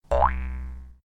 trampoline-cartoon-01.ogg